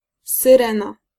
Ääntäminen
Synonyymit signaalhoorn alarmtoestel bel Ääntäminen Tuntematon aksentti: IPA: /sirɛnə/ Haettu sana löytyi näillä lähdekielillä: hollanti Käännös Ääninäyte 1. syrena {f} Suku: f .